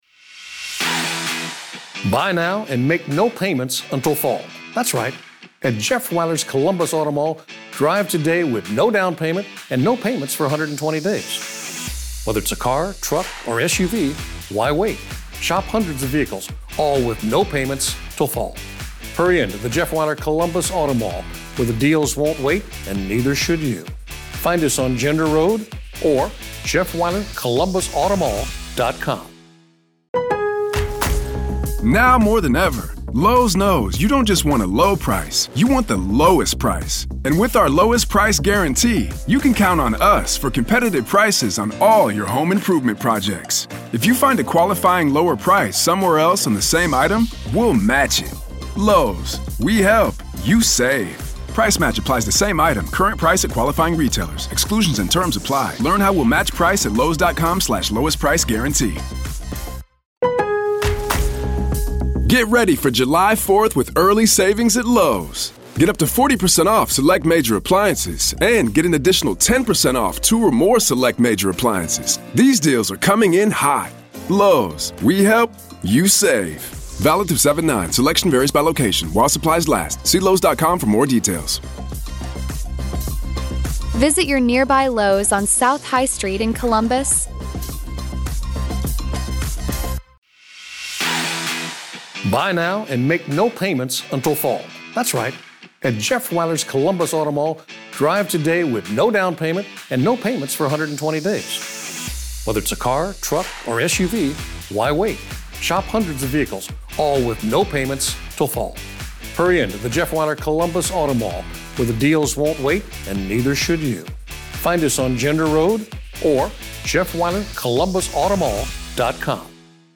COURT AUDIO: AZ VS LORI DAYBELL DAY 1 JURY SELECTION PART 2
In a heated hearing held Monday afternoon in Arizona, Lori Vallow Daybell passionately argued motions in her ongoing case.